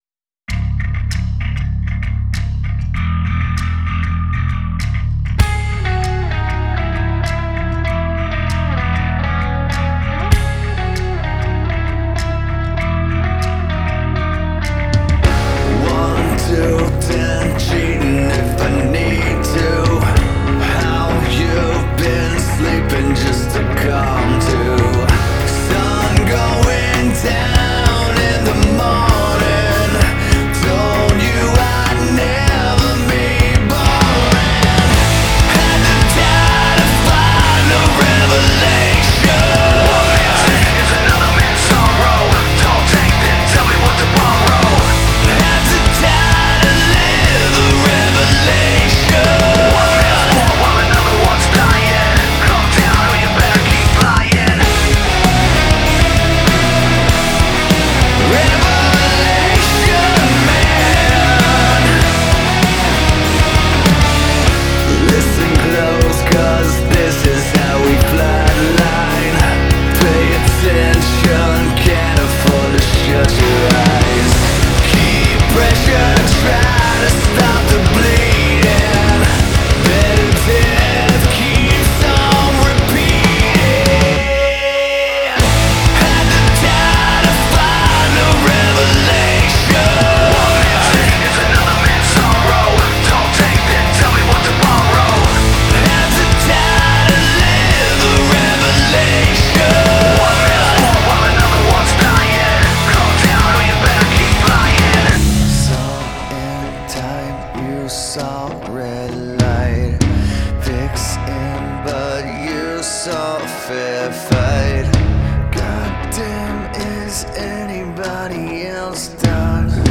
Genre: Alternative Metal